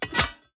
UI_Pickup_Bronze.ogg